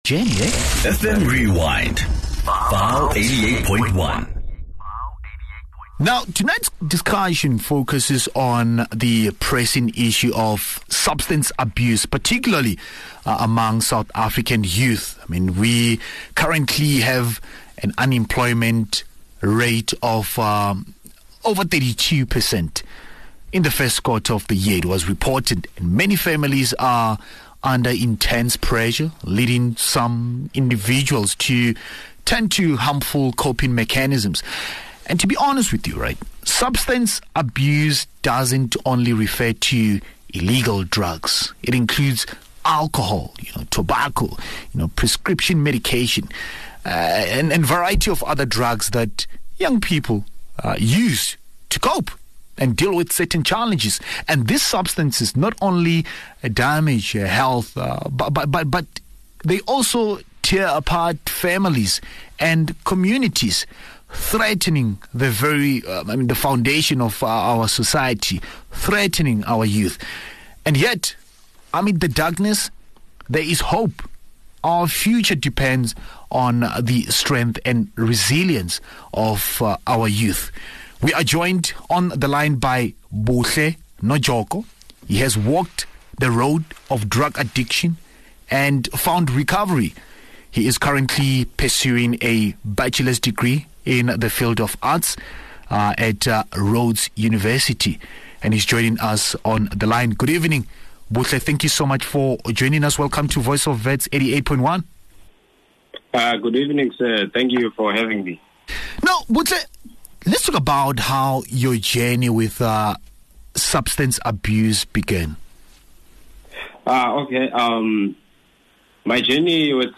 In conversation with, a BA student at Rhodes University in Makhanda.